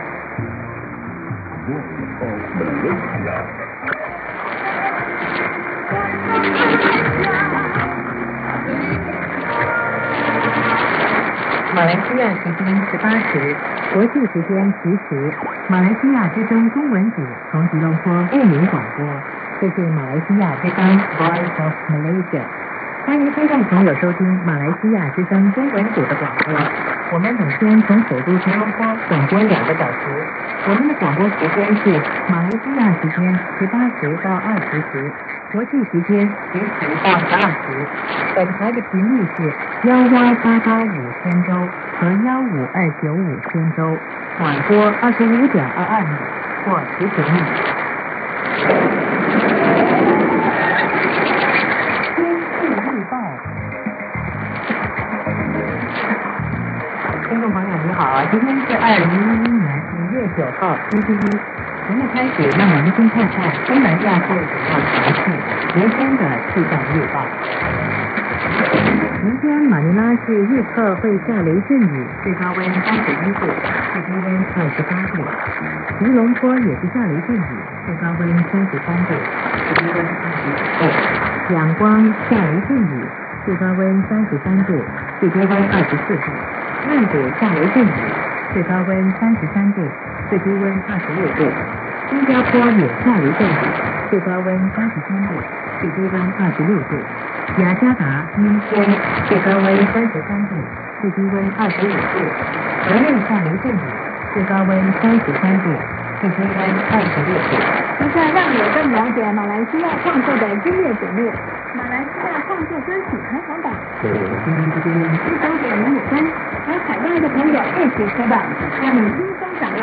ID: identification announcement
ST: signature tune/jingle